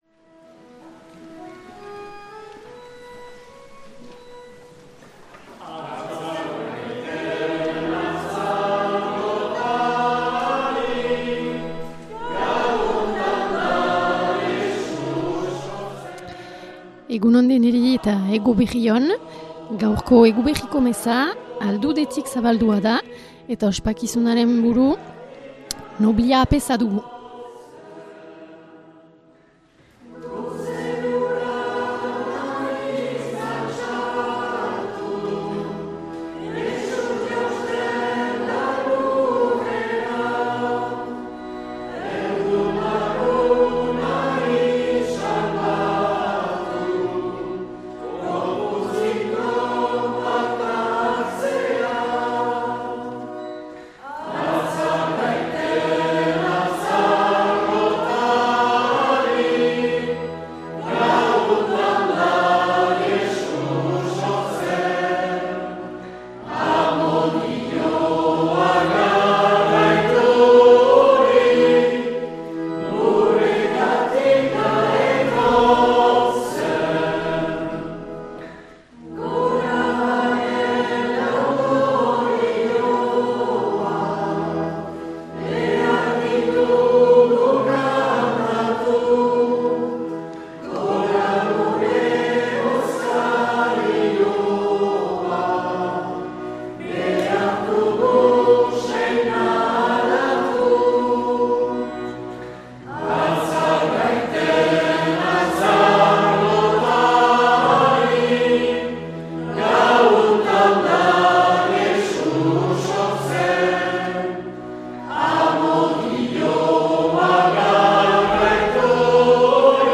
2025-12-24 Eguberri gaua - Aldude